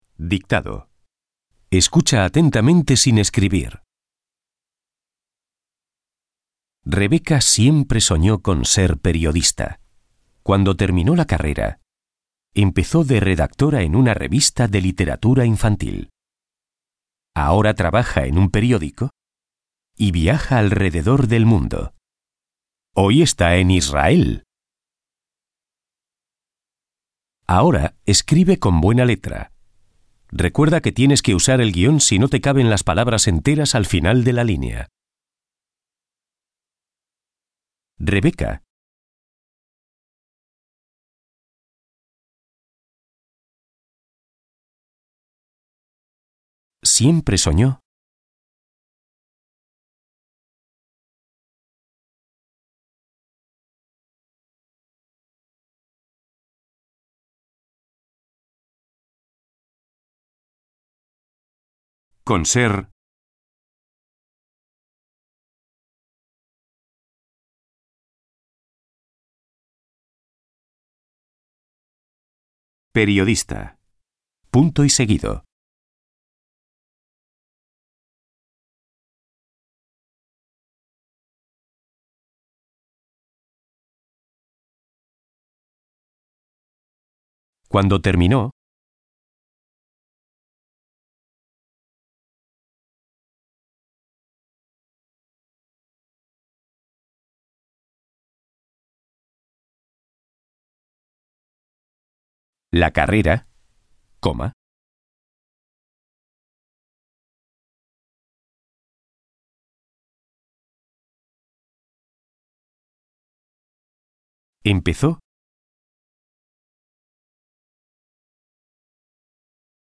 Dictado-4-5-20.mp3